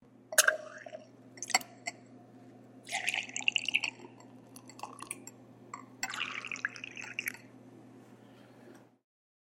beer_bar.ogg